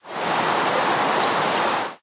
rain4.ogg